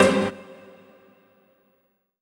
HOUSE128.wav